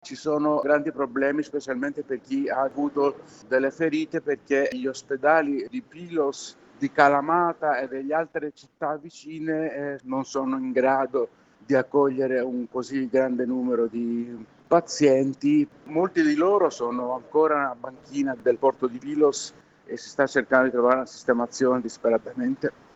Abbiamo sentito il giornalista greco